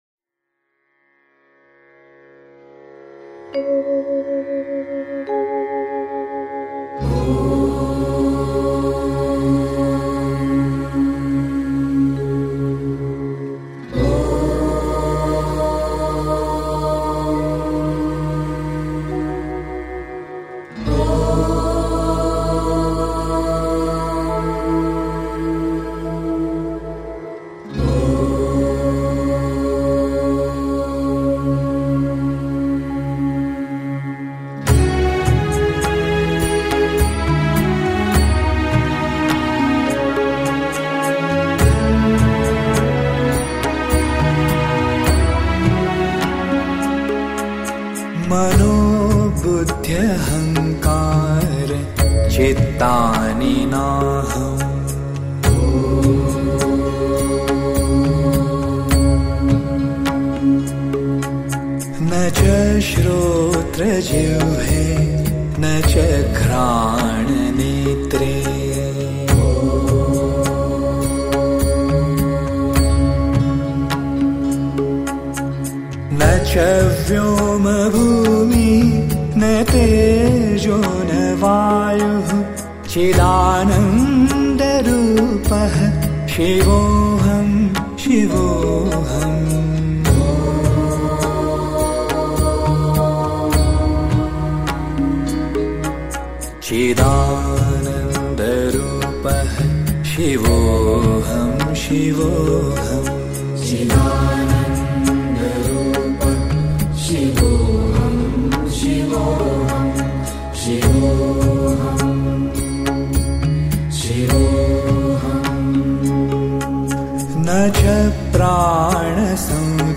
Devotional Songs > Shiv (Bholenath) Bhajans